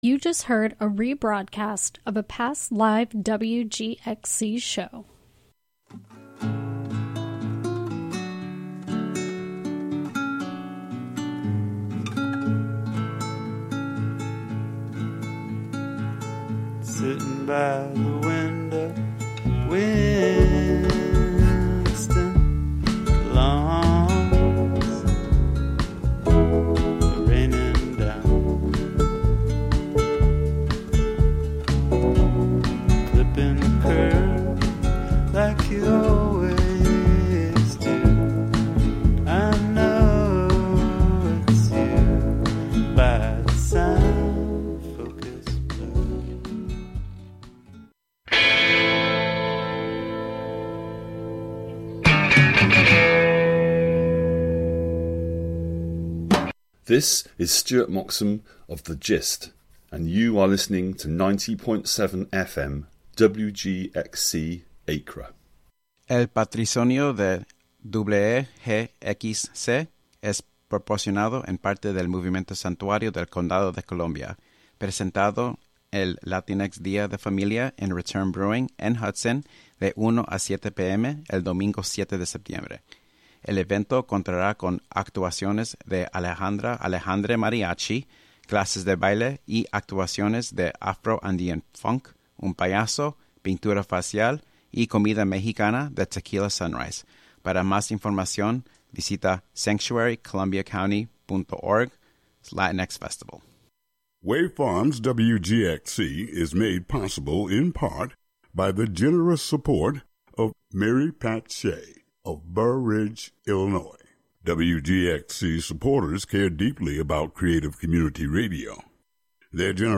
Featuring gospel, inspirational, soul, R&B, country, christian jazz, hip hop, rap, and praise and worship music of our time and yesteryear, interwoven with talk, interviews, and spiritual social commentary